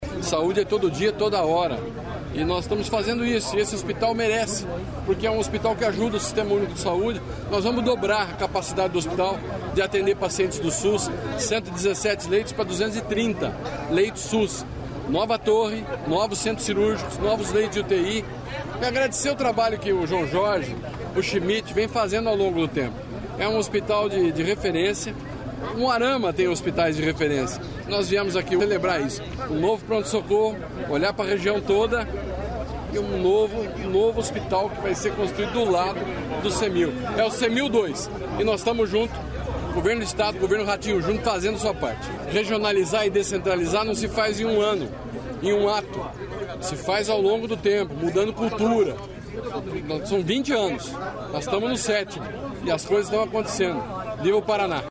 Sonora do secretário da Saúde, Beto Preto, sobre o Hospital Cemil em Umuarama